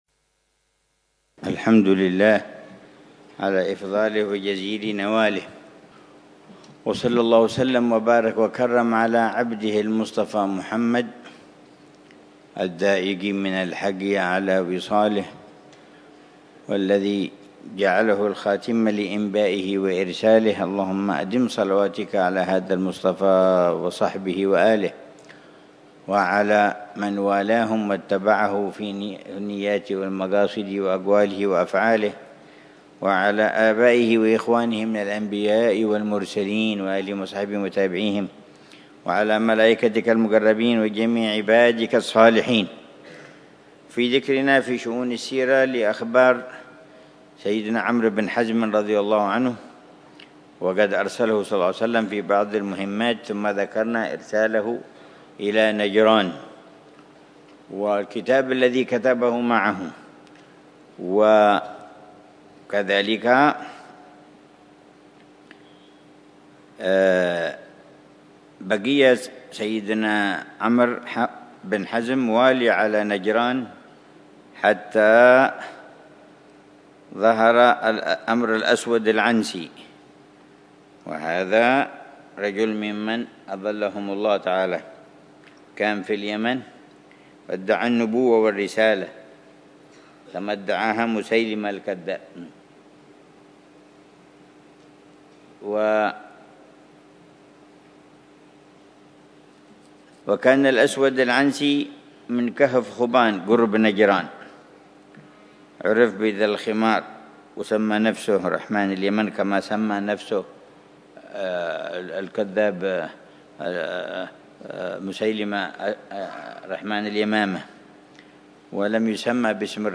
الدرس الرابع من دروس السيرة النبوية التي يلقيها العلامة الحبيب عمر بن محمد بن حفيظ، ضمن دروس الدورة التعليمية الثلاثين بدار المصطفى بتريم للدر